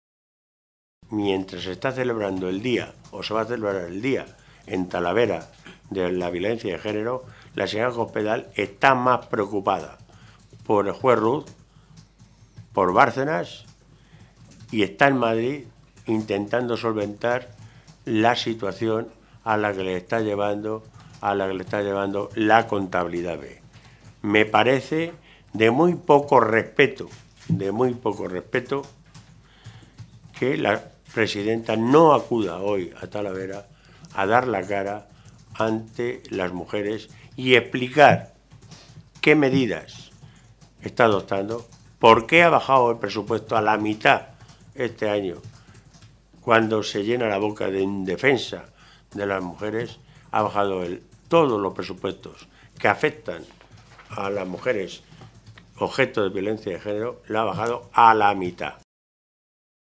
En una comparecencia ante los medios de comunicación esta mañana en Toledo
Cortes de audio de la rueda de prensa